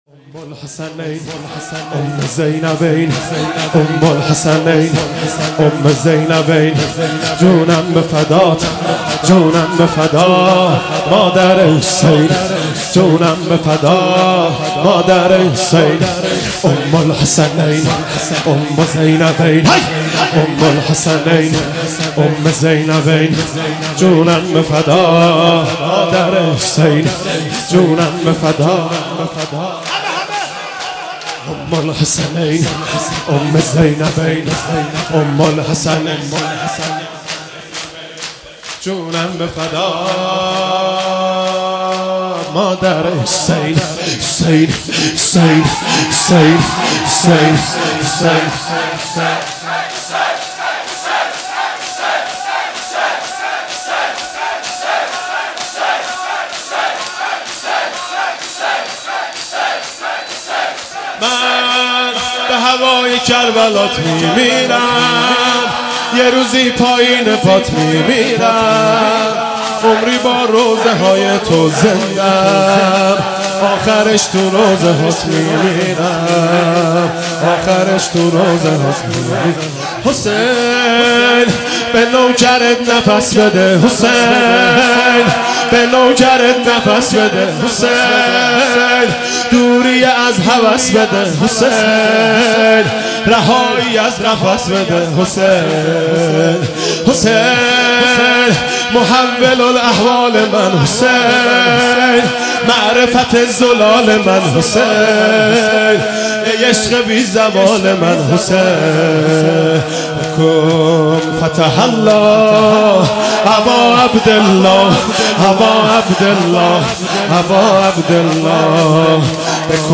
هیئت ثارالله زنجان